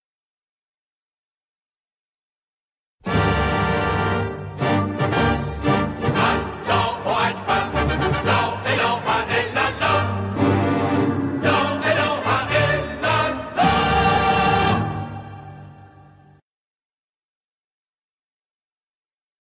برنامه تلویزیونی